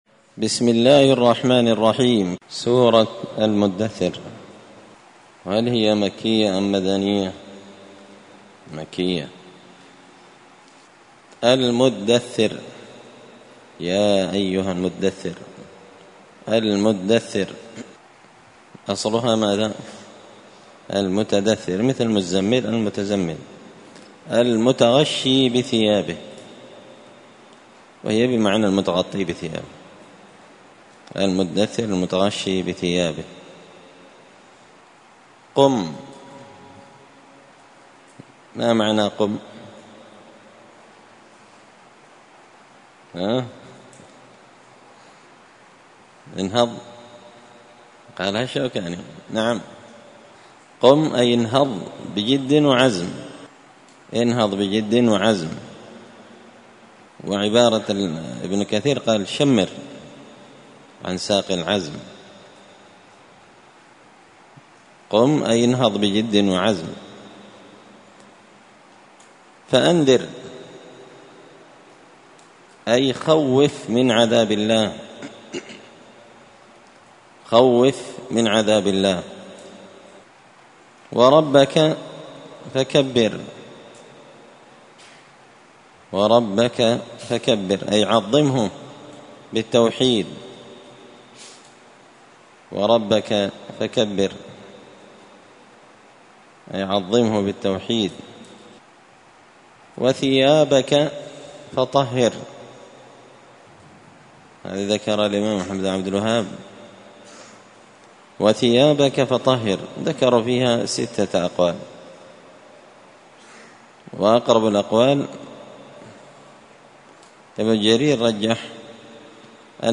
(جزء تبارك سورة المدثر الدرس 94)